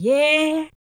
45 RSS-VOX.wav